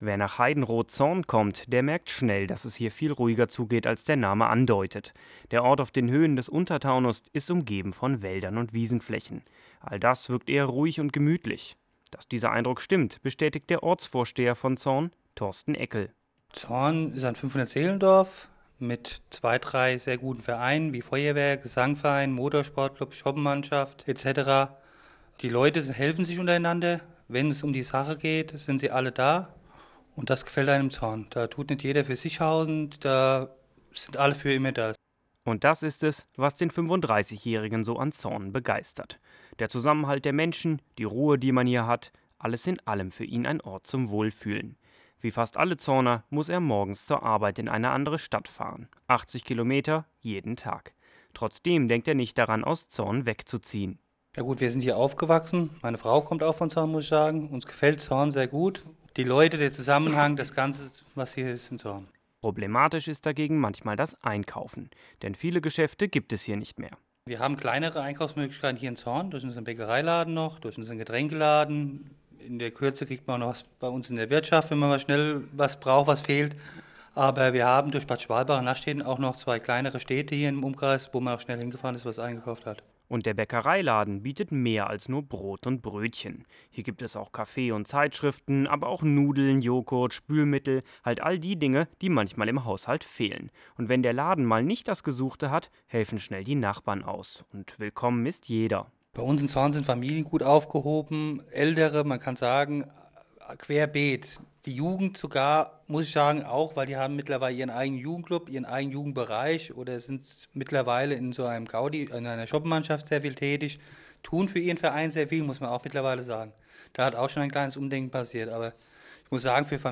Hörfunkbeitrag auf HR4